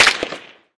light_crack_06.ogg